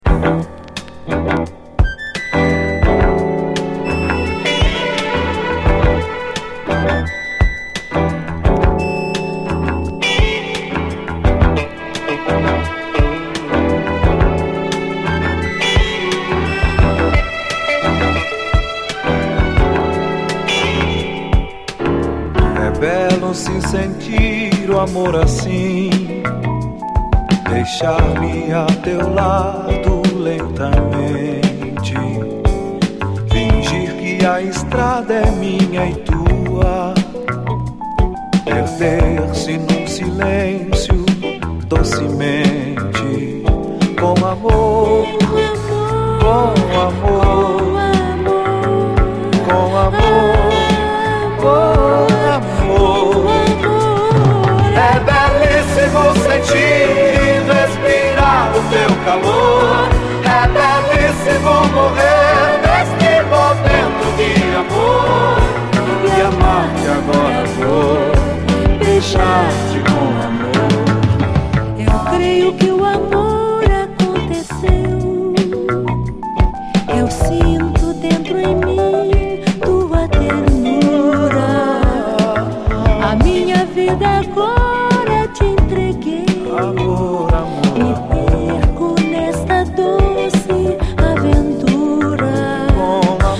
アコギの刻みのイントロから引き込まれる名曲！